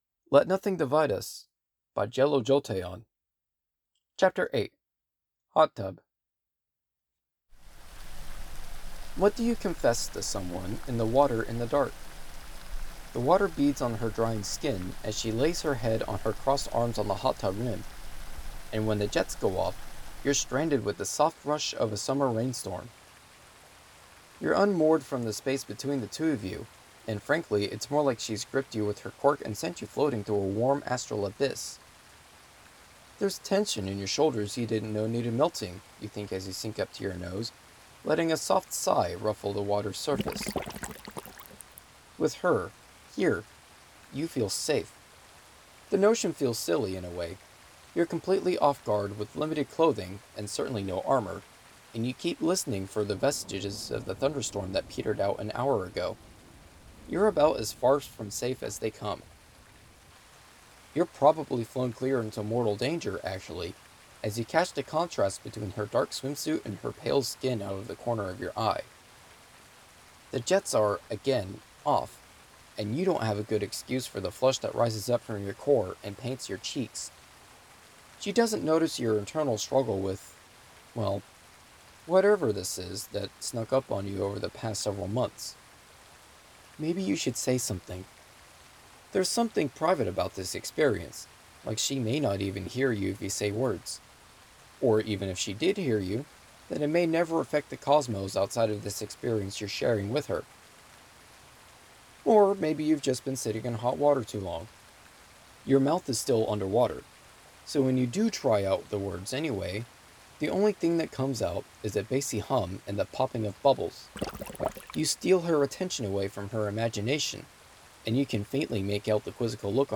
Narrator Voice of Izuku Midoriya
Voice of Ochako Uraraka
" Stirring in a cup of tea 1 " by Anti-HeroAnnie This sound is licensed under CC BY-NC 4.0 .